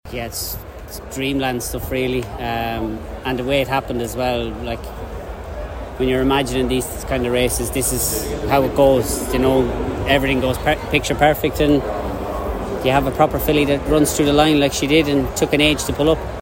interviewing jockey Chris Hayes after Epsom Oaks win on Ezeliya.